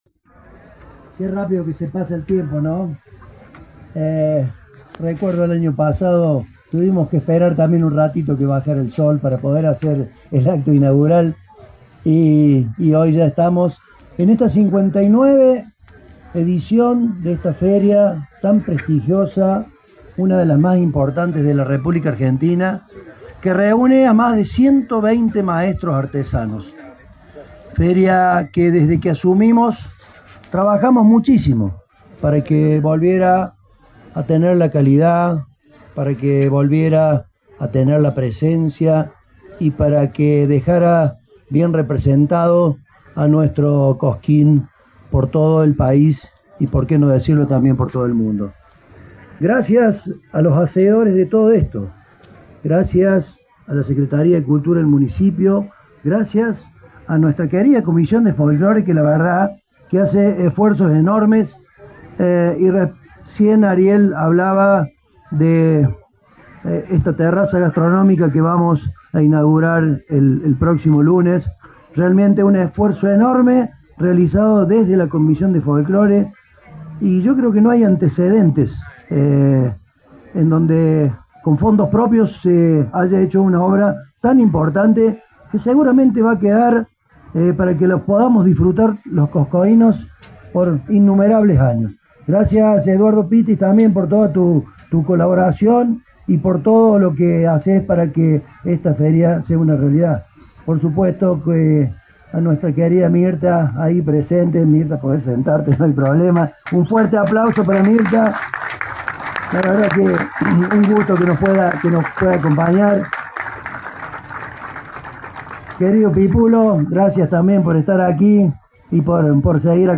En el marco de su discurso el Intendente Raúl Cardinali remarcó la iniciativa de transformar a Cosquín en un foro cultural de la Provincia y del País.